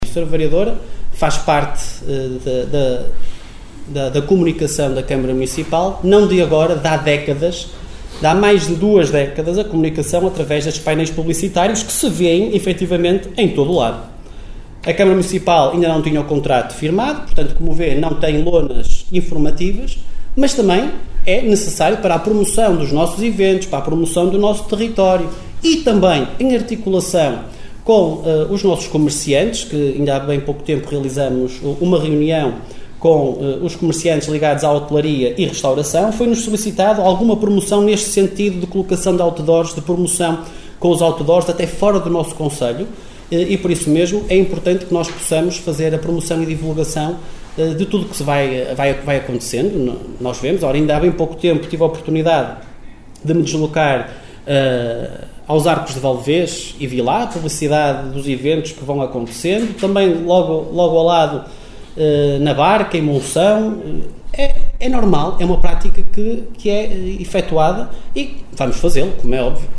Excertos da última reunião camarária, realizada ontem no Salão Nobre dos Paços do concelho.